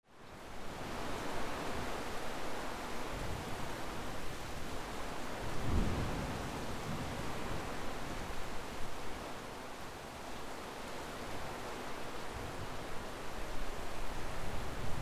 Background Sound Effects
thunderstorm_01-1-sample.mp3